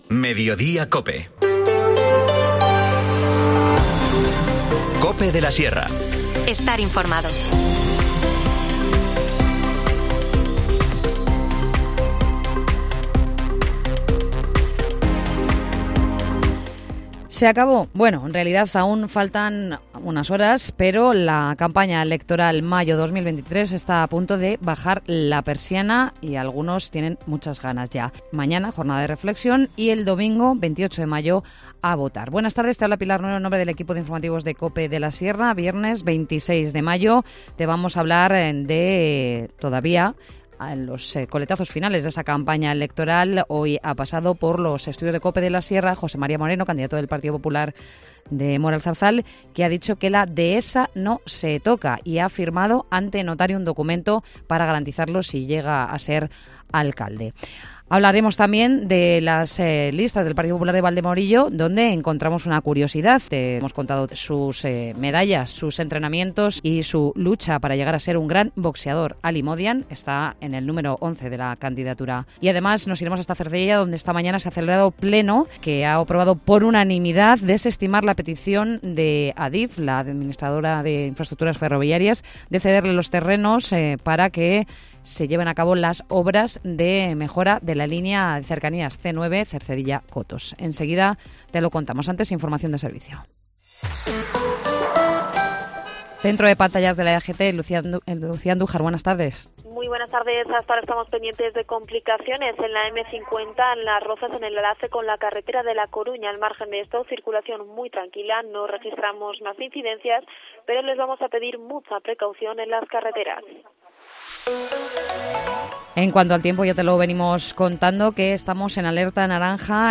Informativo Mediodía 26 mayo